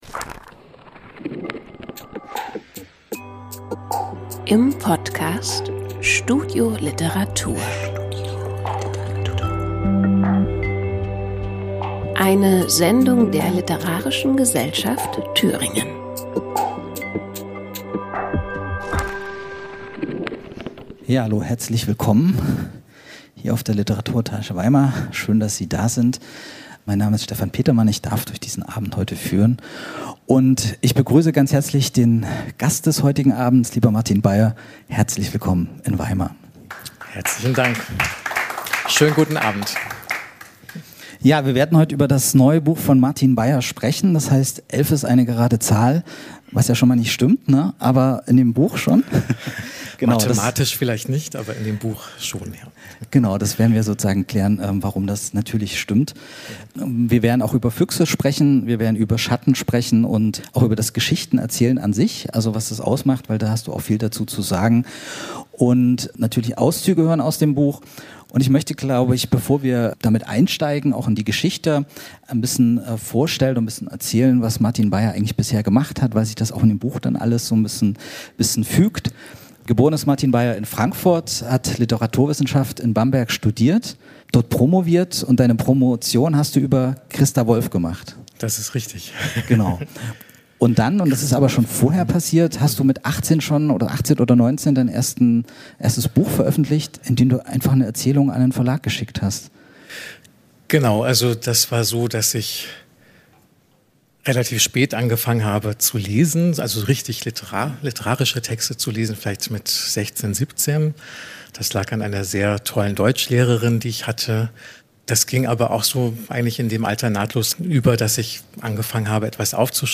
Aufzeichnung einer Lesung in der Weimarer LiteraturEtage vom 16.01.2026